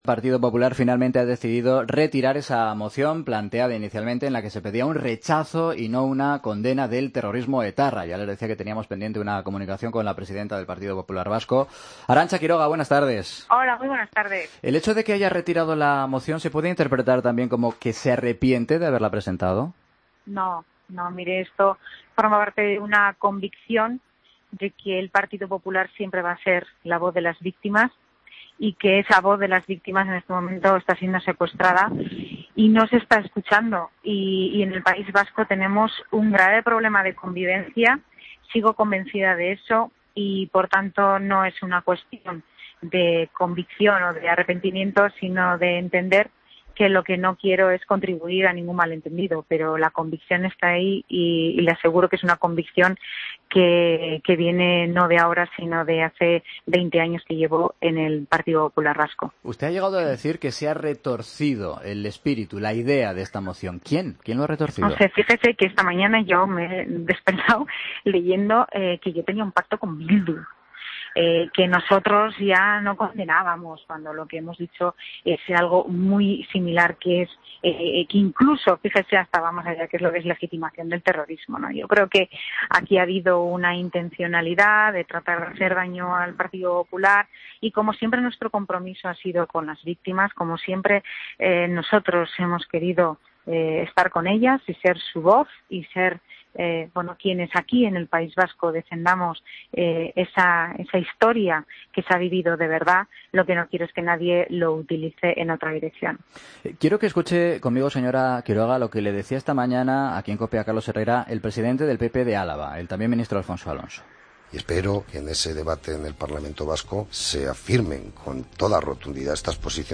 Escucha la entrevista completa a Arantza Quiroga